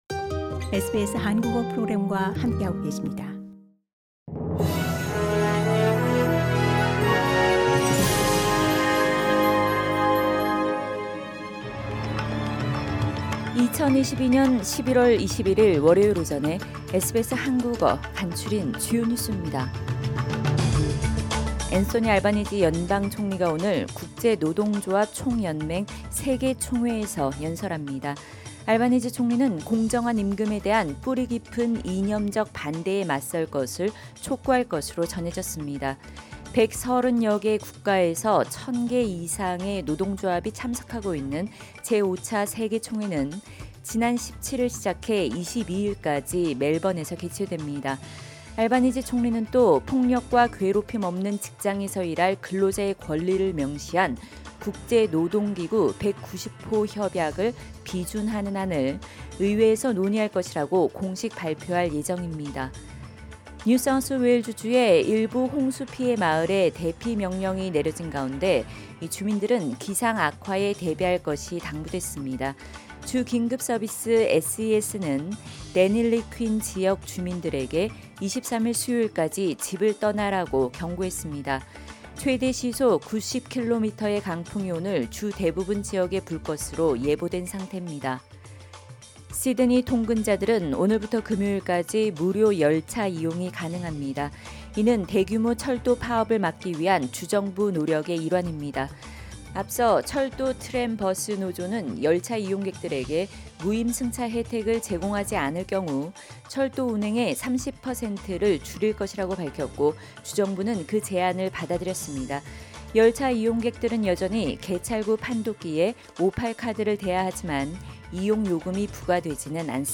2022년 11월 21일 월요일 아침 SBS 한국어 간추린 주요 뉴스입니다.